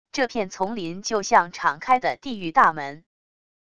这片丛林就像敞开的地狱大门wav音频生成系统WAV Audio Player